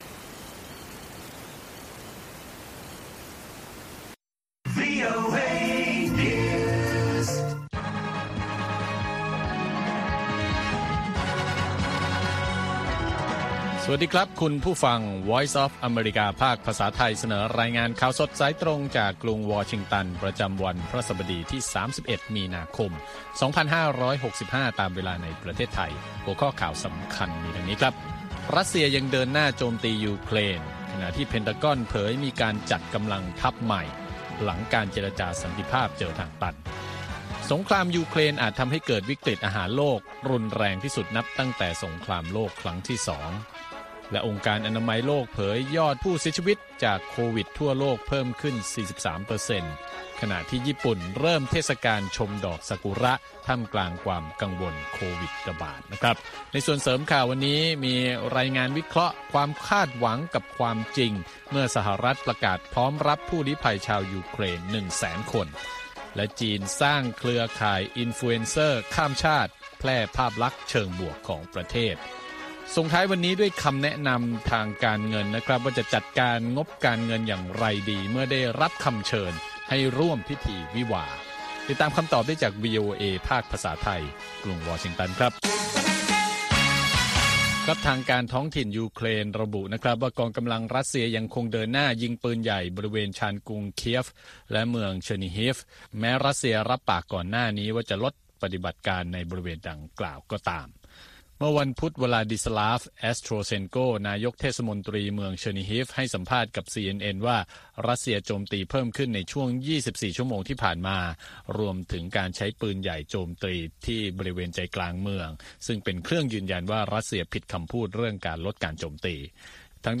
ข่าวสดสายตรงจากวีโอเอ ภาคภาษาไทย 8:30–9:00 น. ประจำวันพฤหัสบดีที่ 31 มีนาคม 2565 ตามเวลาในประเทศไทย